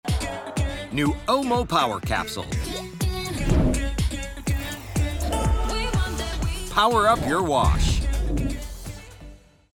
Male
Warm, intelligent American male voice for brands that value trust, clarity, and real human connection. I bring an actor's instinct, musicality, and restraint to every read—delivering confident, natural performances that feel grounded, believable, and never synthetic.
Television Spots